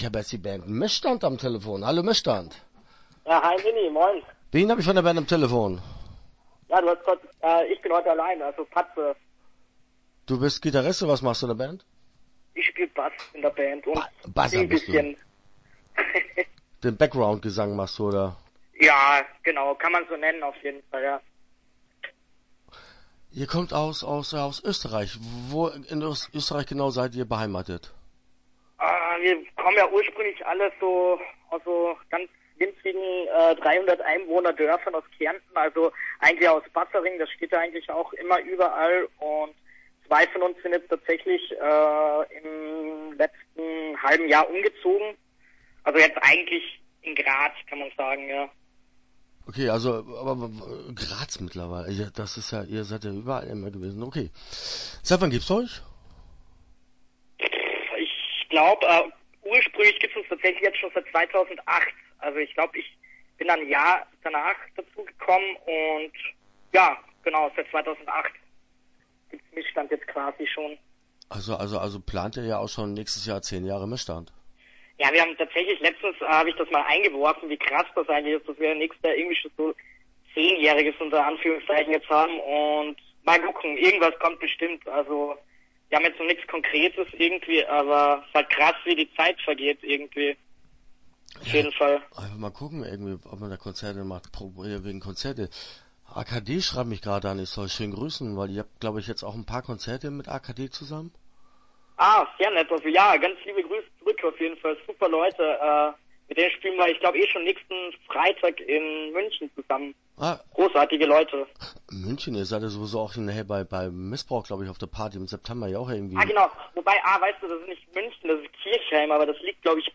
Start » Interviews » Missstand